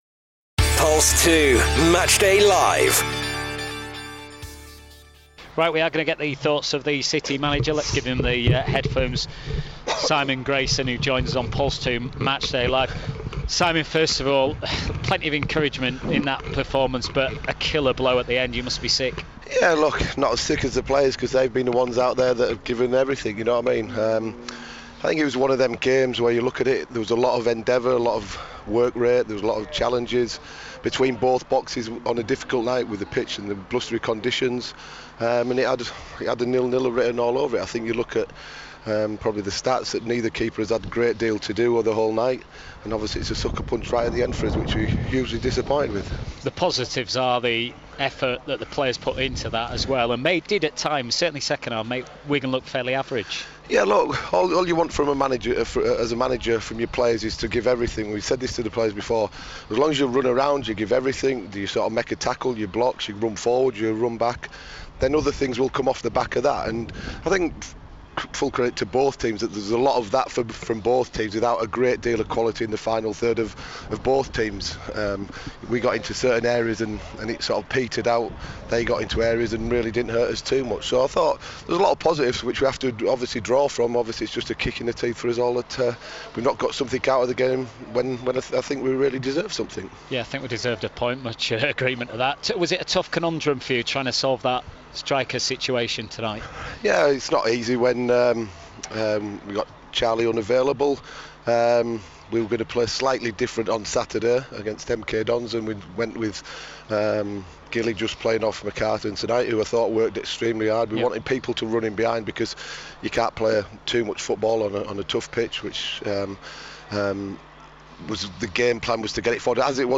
Simon Grayson Post Match Interview | Bradford City vs Wigan Athletic